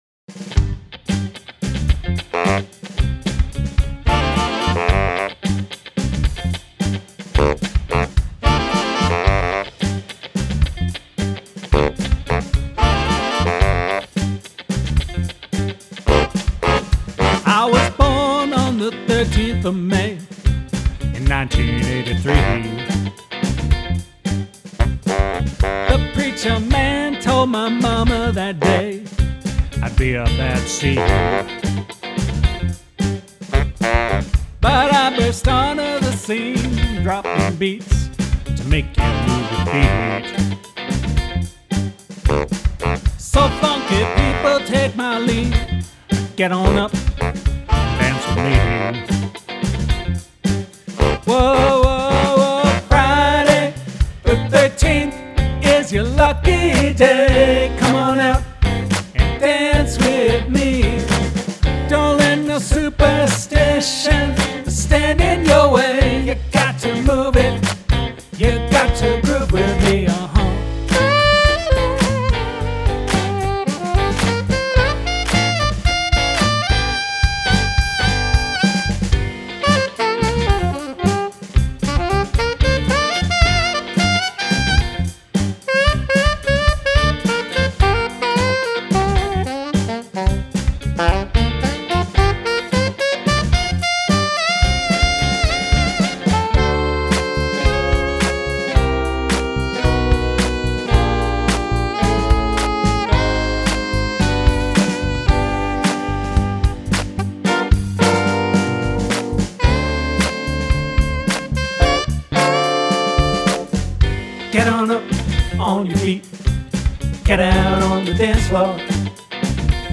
write a feel-good song with happy lyrics and upbeat music
:!: :!: :!: Old man funky jam!